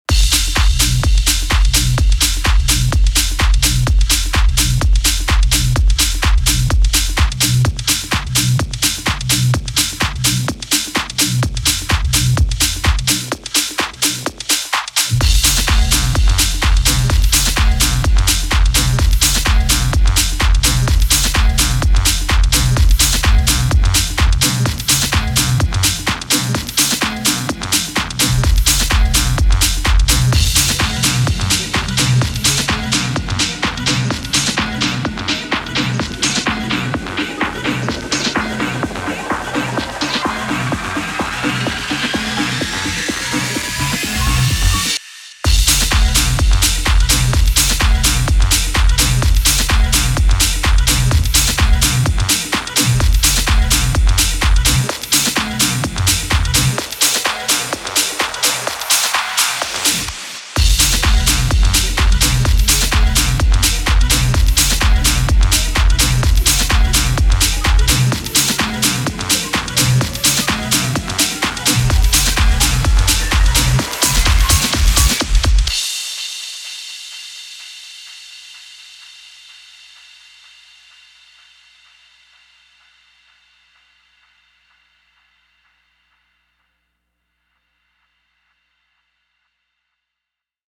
Sorry people, these are pushing it into the harder house side but I’m thinking a house DJ might play them :slight_smile:
Im truly blessed to have collected some samplers with sequencers over time and these little jams come from Pioneer Toraiz SP-16.
The Pioneer’s are a little limited in terms of sequencing longer beats so I bounced the tracks to audio loops and dropped them into Ableton to make these 48 bar beats.